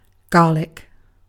Ääntäminen
UK : IPA : /ˈɡɑː.lɪk/